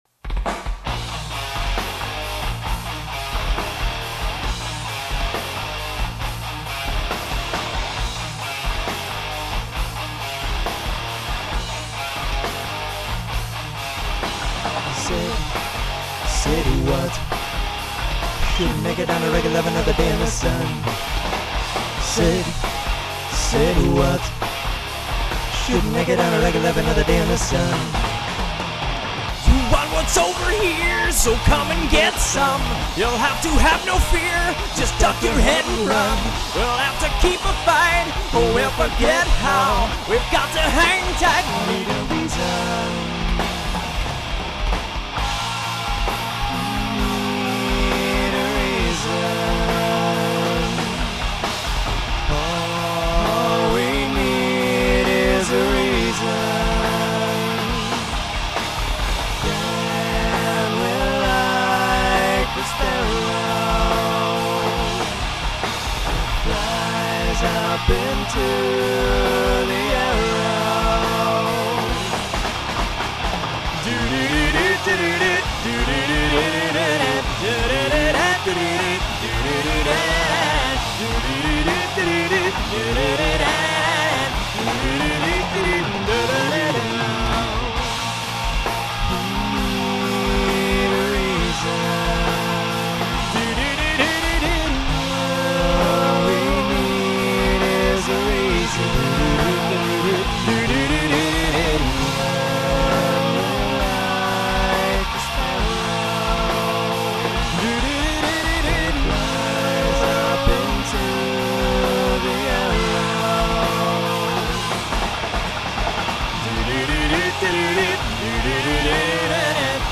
Take One - Burst Scratch - Mostly crap
Unfortunately we had to give our pro studio mic back to it's owner.